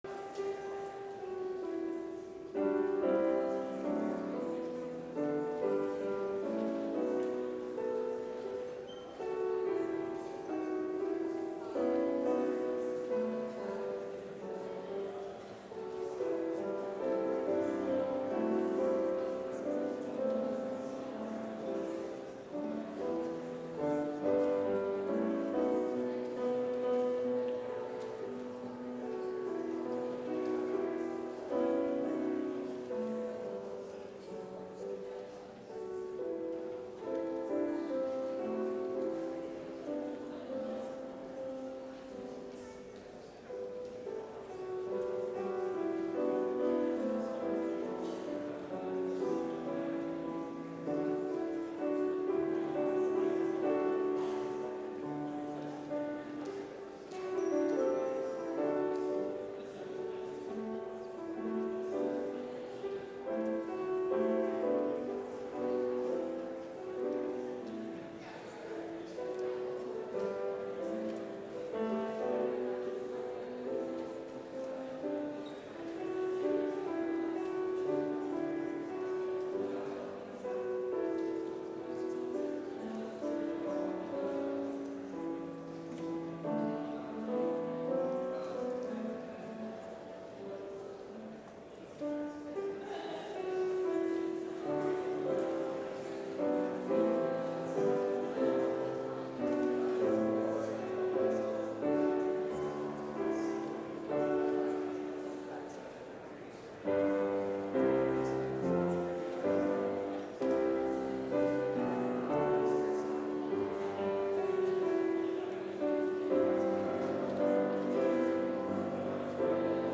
Complete service audio for Chapel - Thursday, September 7, 2023